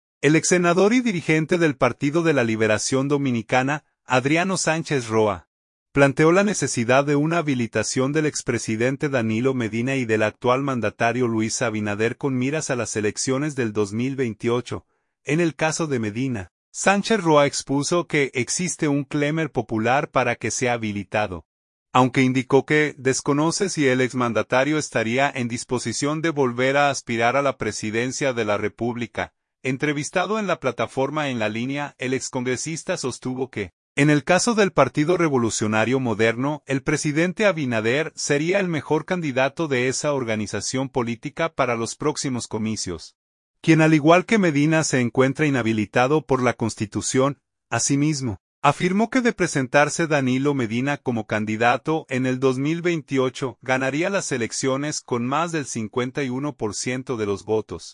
Entrevistado en la plataforma “En La Línea”, el excongresista sostuvo que, en el caso del Partido Revolucionario Moderno, el presidente Abinader sería el mejor candidato de esa organización política para los próximos comicios, quien al igual que Medina se encuentra inhabilitado por la Constitución.